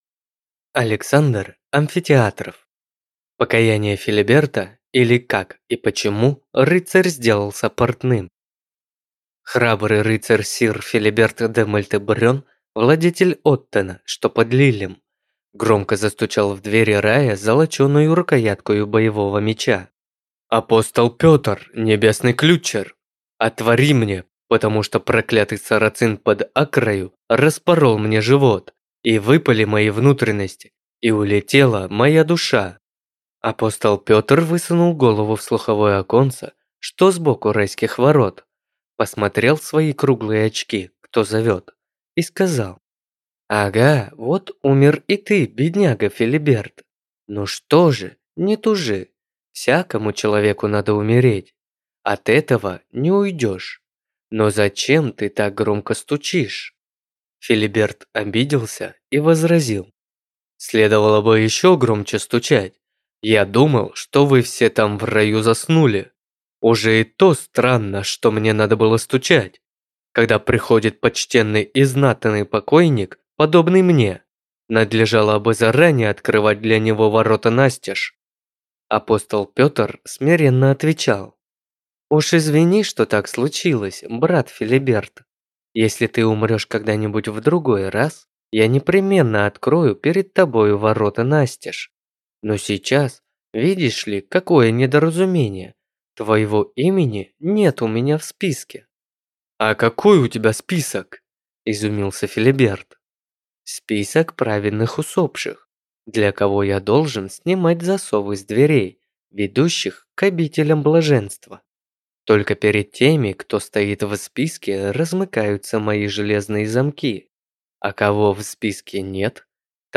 Аудиокнига Покаяние Филиберта, или Как и почему рыцарь сделался портным | Библиотека аудиокниг